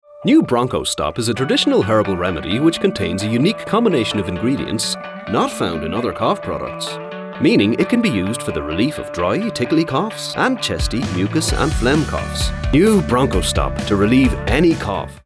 Warm, Reassuring Tones, From Low and Rich to Bright and Energetic. Northern Irish
Commercial, Friendly, Energetic, Upbeat
Irish